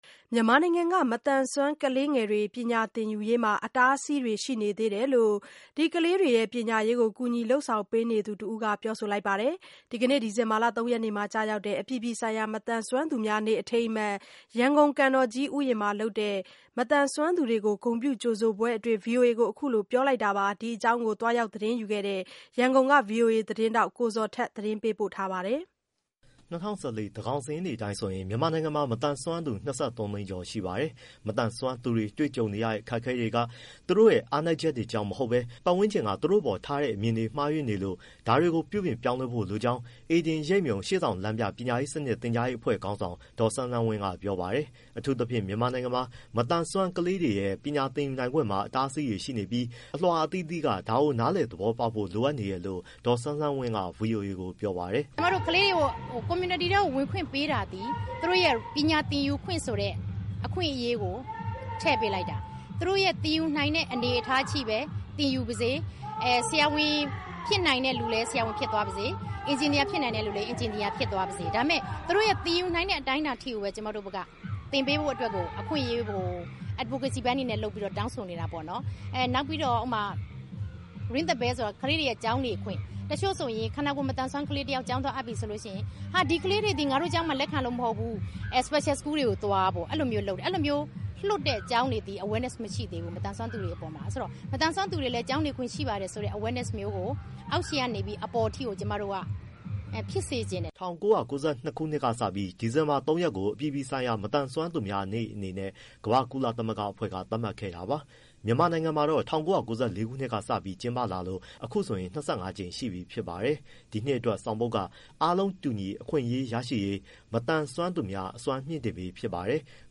ဒီကနေ့ ဒီဇင်ဘာ ၃ရက်မှာ ကျရောက်တဲ့ အပြည်ပြည်ဆိုင်ရာ မသန်စွမ်းသူများနေ့အထိမ်းအမှတ် ရန်ကုန်ကန်တော်ကြီးဥယျာဉ်မှာလုပ်တဲ့ မသန်စွမ်းသူတွေကို ဂုဏ်ပြုကြိုဆိုပွဲအတွင်း ဗွီအိုအေကို အခုလိုပြောတာပါ။